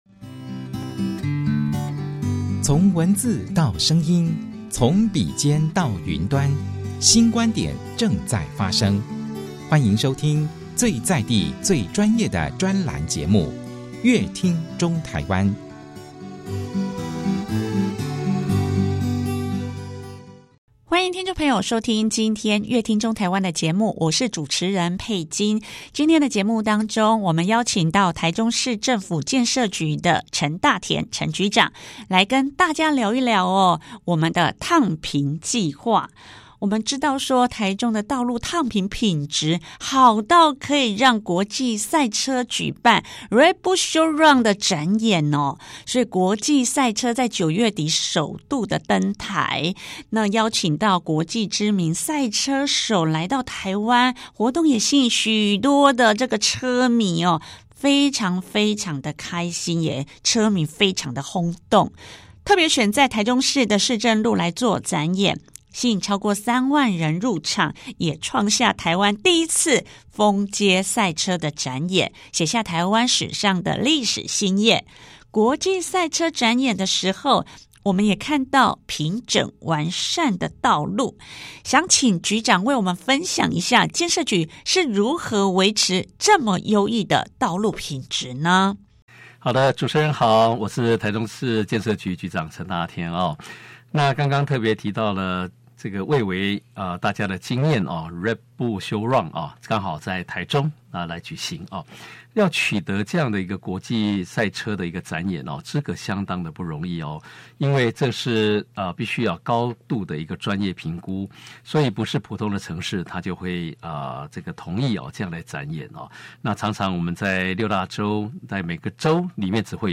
國際賽車展演時我們也看到平整完善的道路，節目中局長為我們分享建設局燙平計畫是如何維持優異的道路品質。